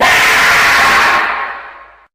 uncanny_jumpscare.ogg